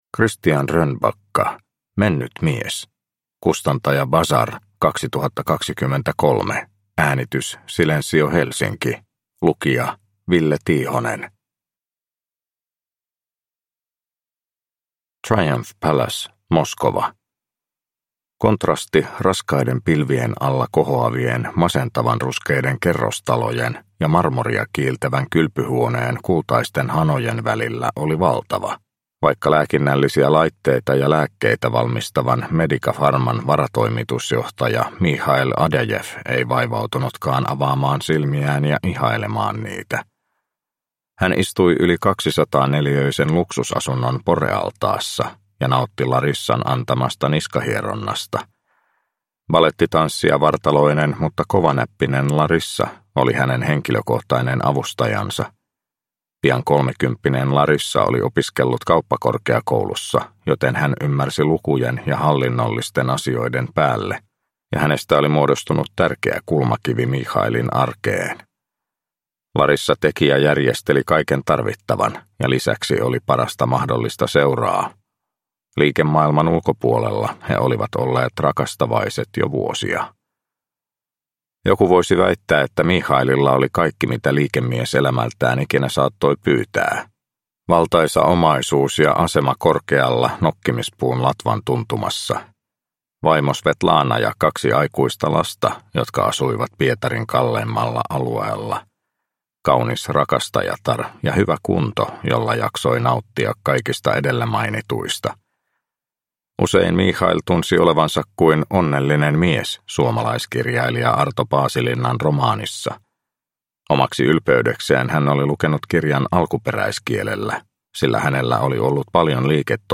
Mennyt mies – Ljudbok – Laddas ner